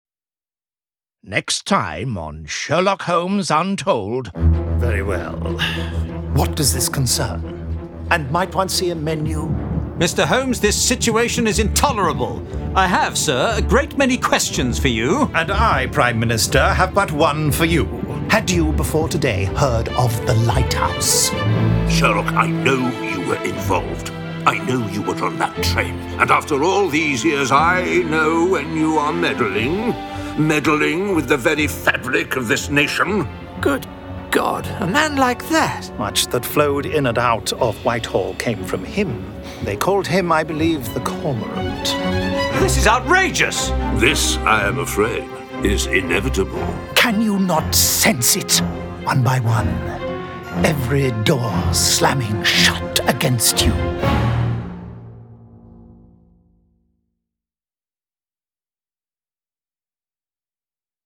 Award-winning, full-cast original audio dramas